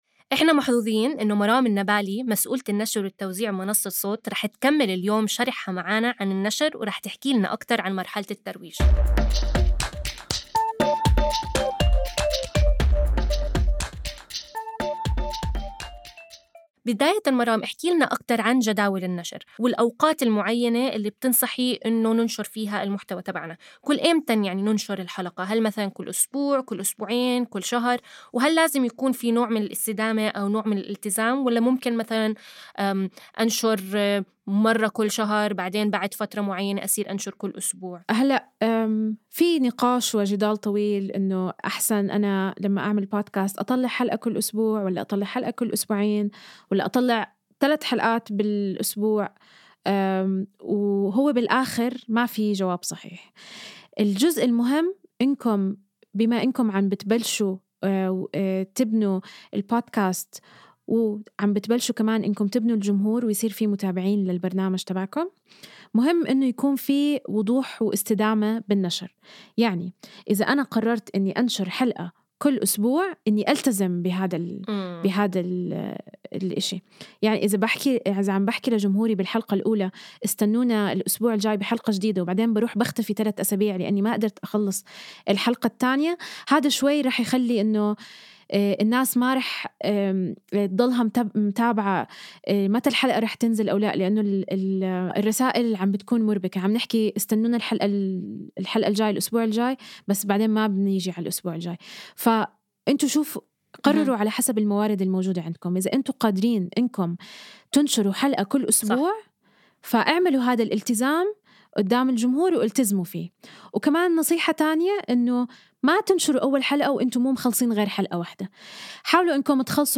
ابتداءً من الحلقة ١٤ وحتى نهاية المساق لن تجدوا تفريغ لهذه الحلقات حيث ستكون الحلقات حوارية وتكمن قيمتها في الاستماع لها.